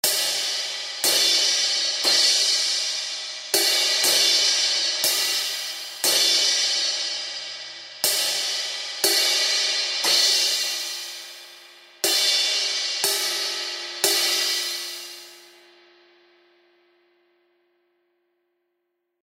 Zildjian-A-Custom-16-Inch-Crash-Cymbal-Loops
Zildjian-A-Custom-16-Inch-Crash-Cymbal-Loops.mp3